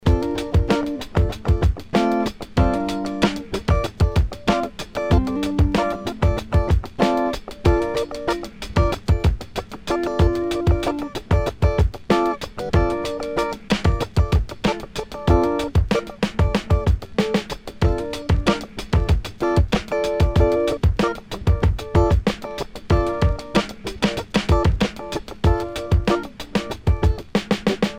Funk
Example 29c uses more ghost notes with a 16th note strumming pattern. The whole piece is done by adding and removing your middle finger on the B string which creates a G7sus2 chord.